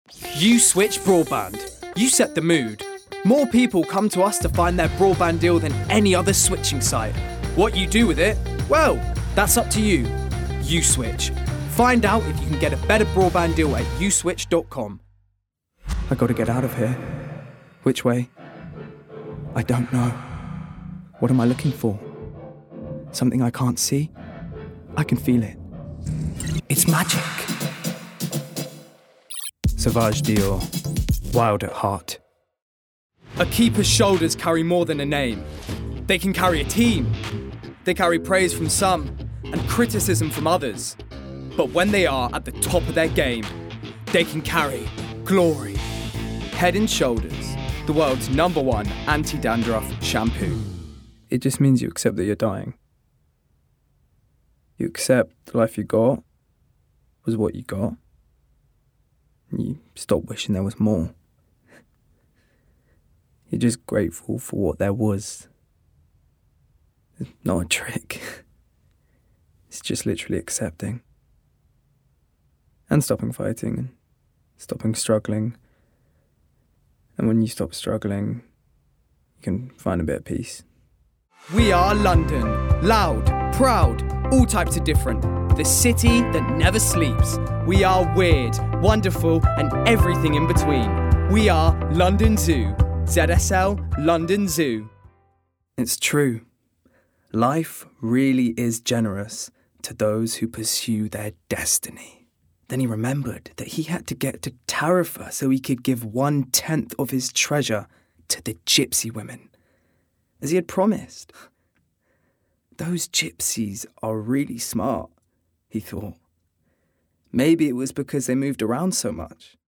Native voice:
Contemporary RP
Voicereel: